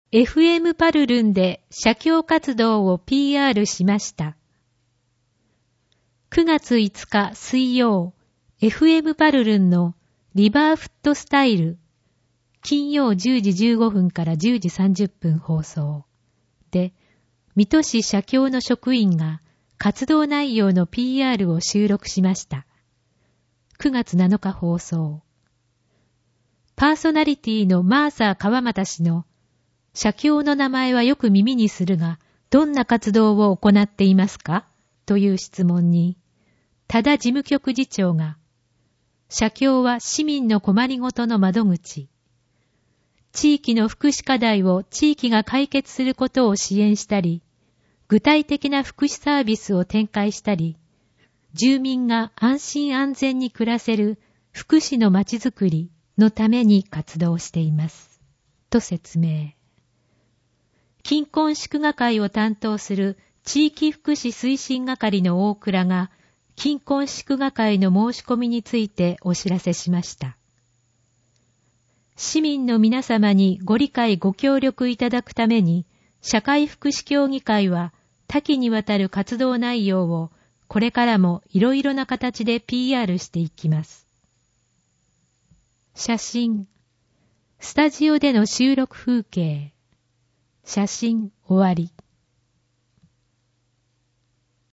音声ガイド
（音声データ作成：音訳ボランティア「こだま」）